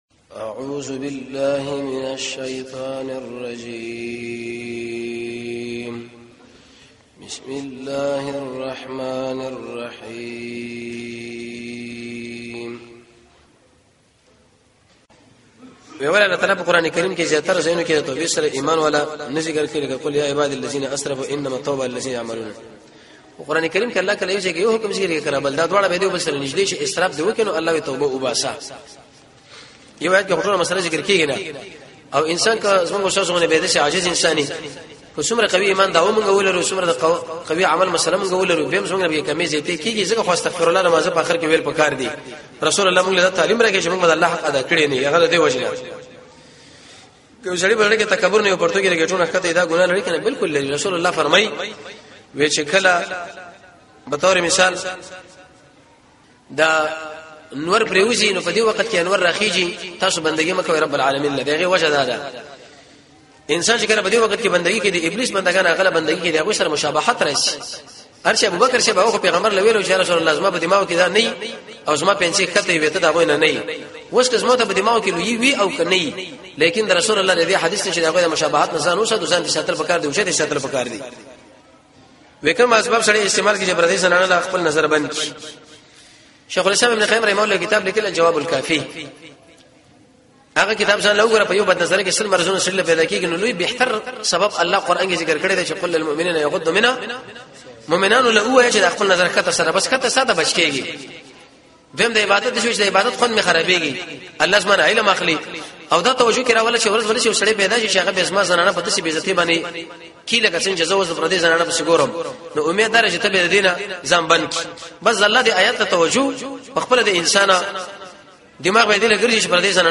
۹ - پوښتنه او ځواب